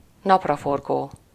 Ääntäminen
IPA: [tuʁ.nə.sɔl]